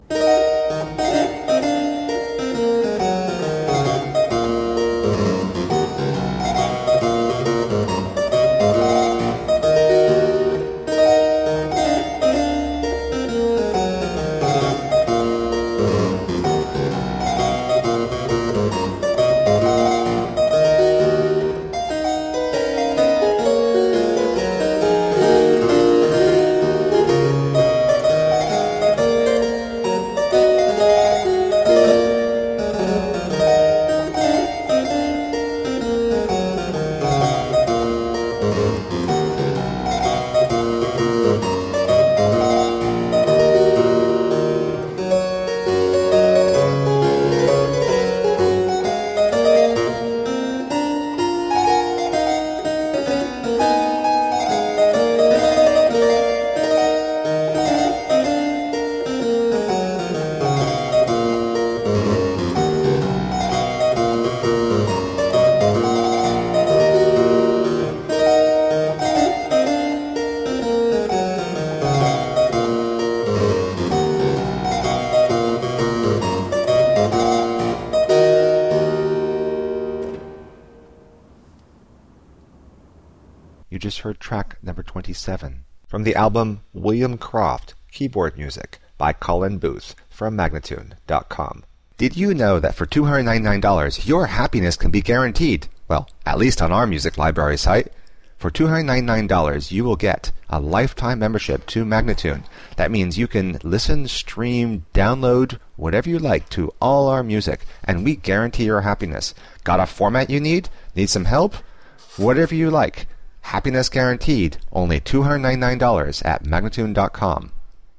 Solo harpsichord music.
Classical, Baroque, Instrumental
Harpsichord